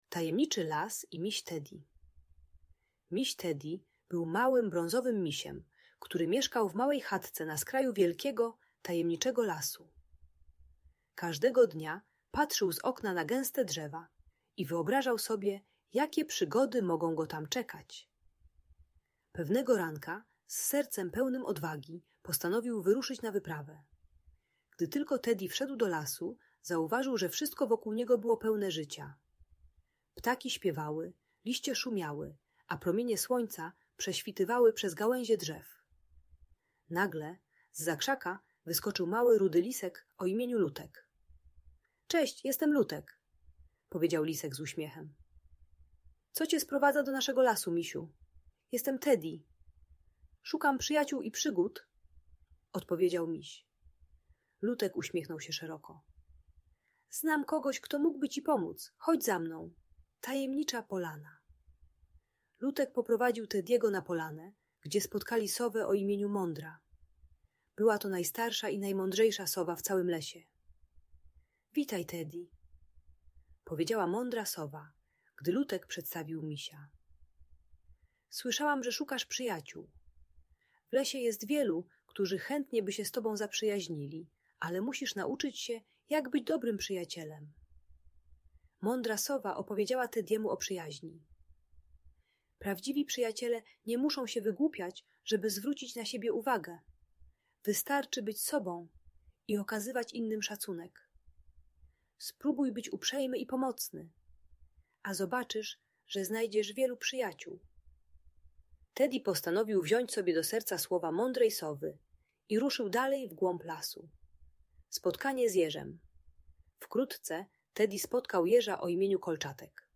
Przygody Misia Teddy w Tajemniczym Lesie - Bunt i wybuchy złości | Audiobajka